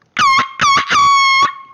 buzinaprolongada.mp3